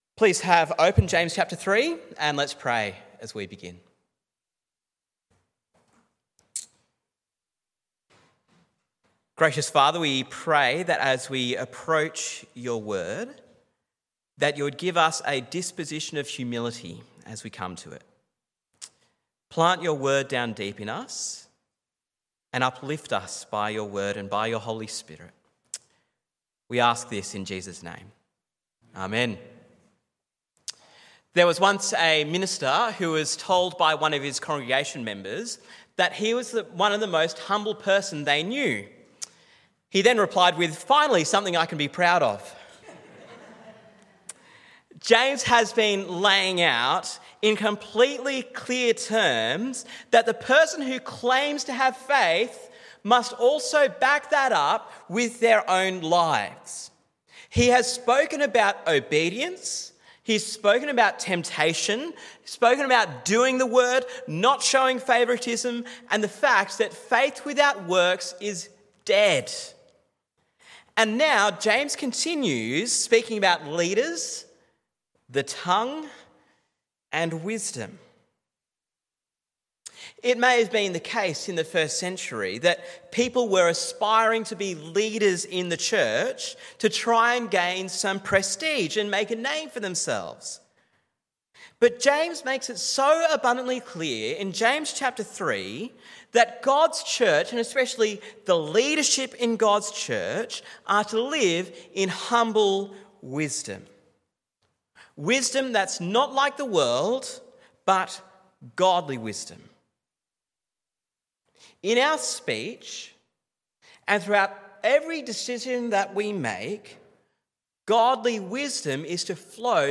Sermon on James 3 - Real Faith Speaks